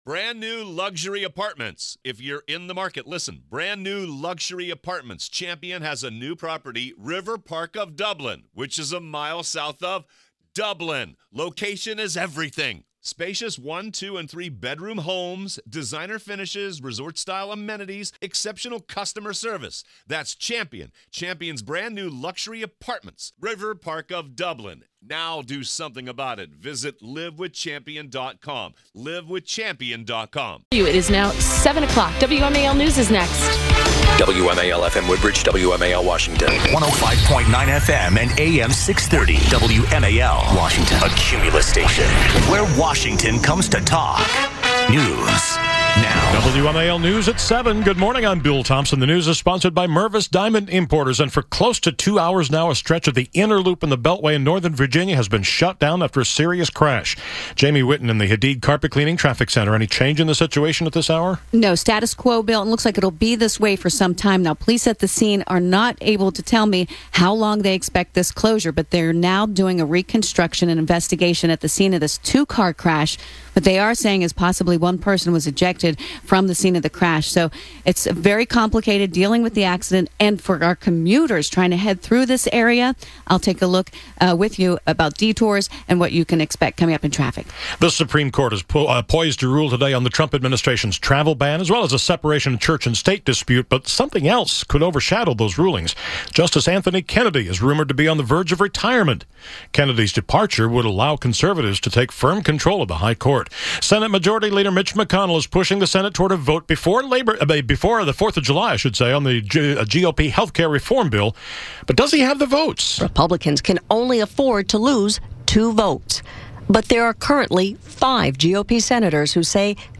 -JOE DIGENOVA - Legal Analyst & Fmr. U.S. Attorney to the District of Columbia